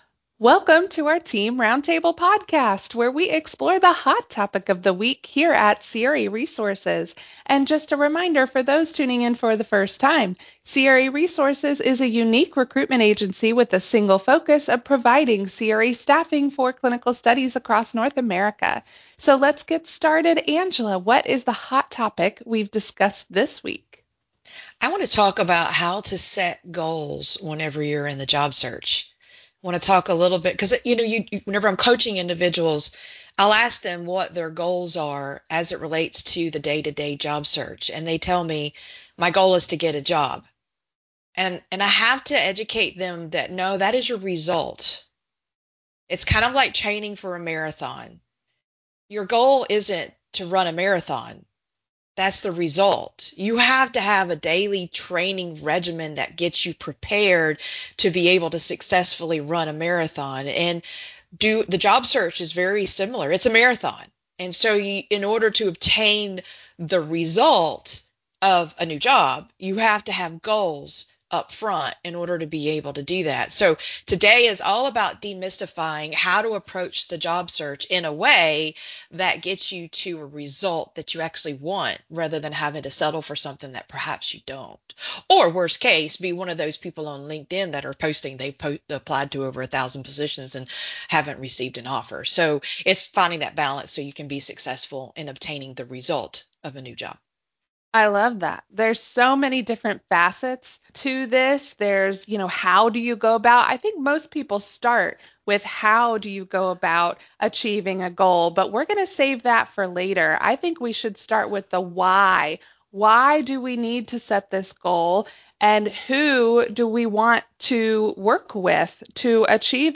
Roundtable: Setting Job Search Goals - craresources